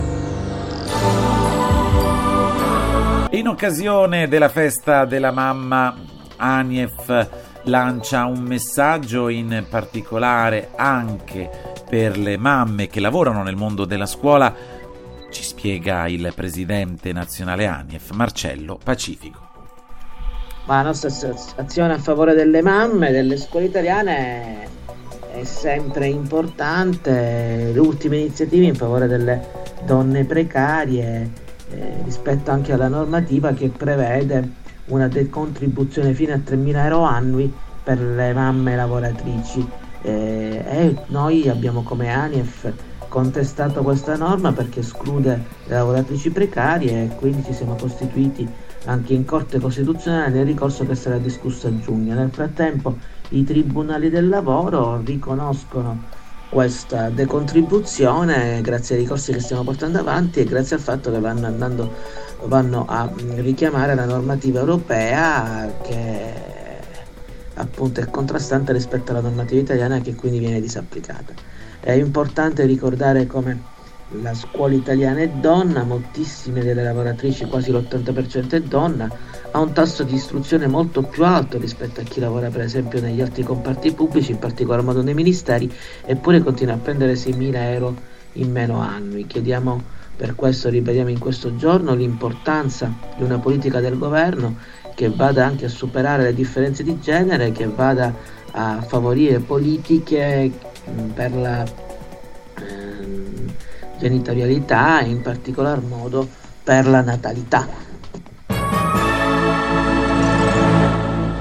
Nel corso della diretta andata in onda su